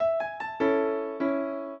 piano
minuet1-12.wav